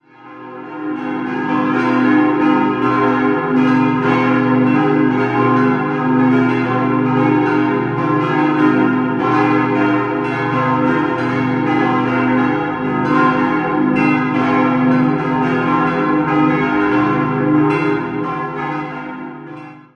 Erweitertes Idealquartett: c'-es'-f'-as'-c'' Die vier größeren Glocken wurden 1901 von der Firma Rüetschi in Aarau gegossen, die kleinste Glocke ist ein Werk aus der berühmten Glockengießerei Rosenlächer (Konstanz) aus dem Jahr 1815.